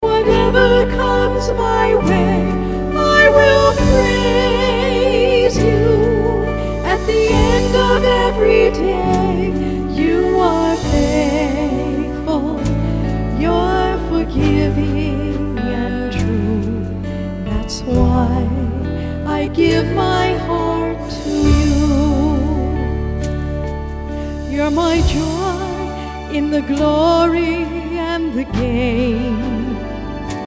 A collection of worship songs